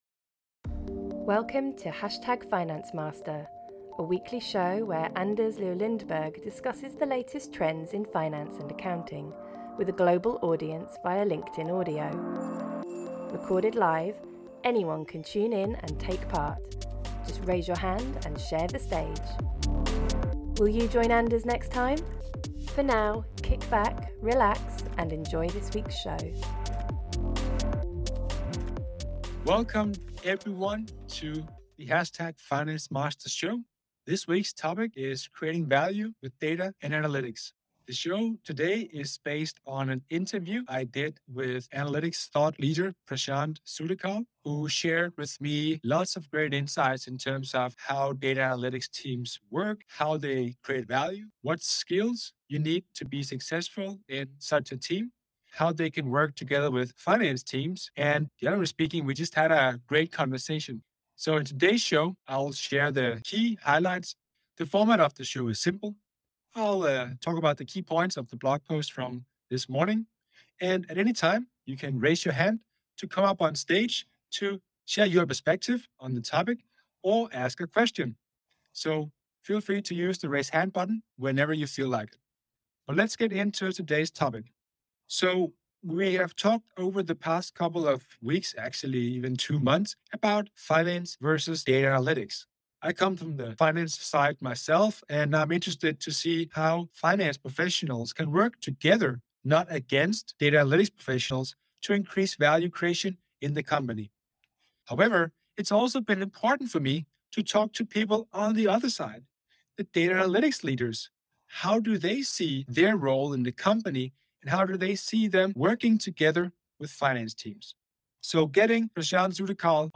and poses these to a live LinkedIn audience.